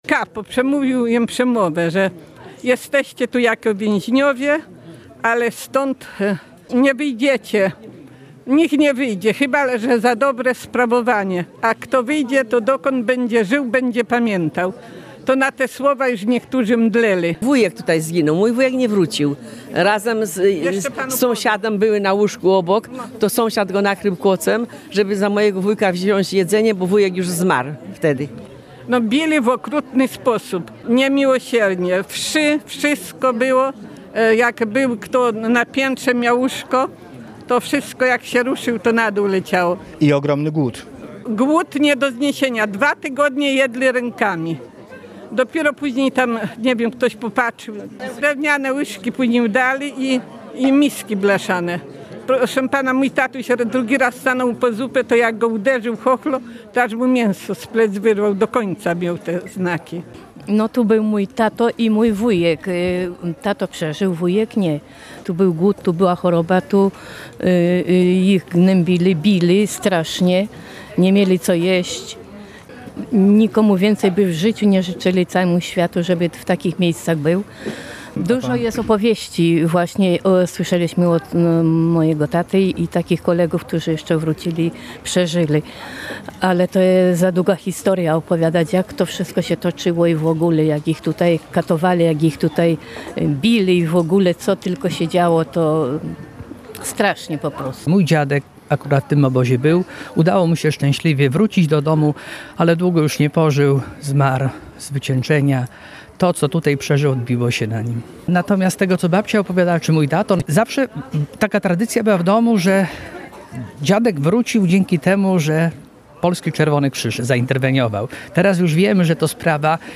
Na wzniesieniu zwanym Górą Śmierci odbyły się uroczystości w hołdzie ofiar obozu.